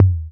D2 TOM-32.wav